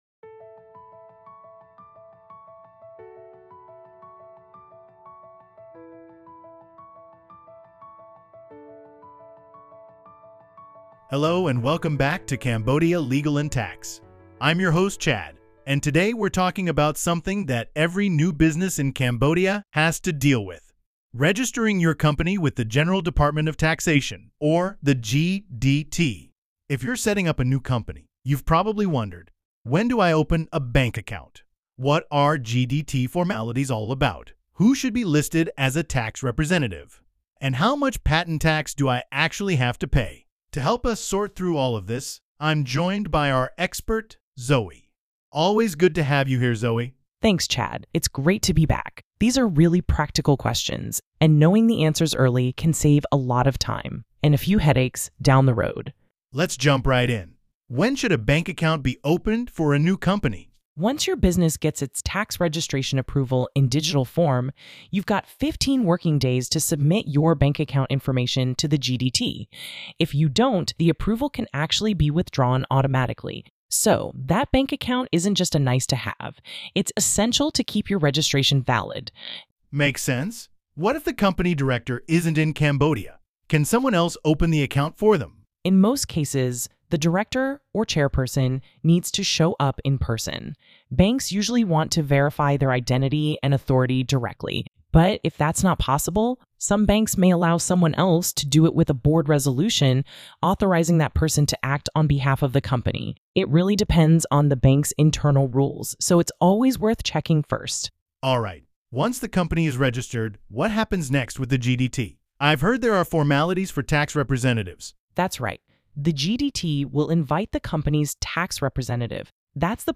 Discussion on topic regarding the registration of new company with the General Department of Taxation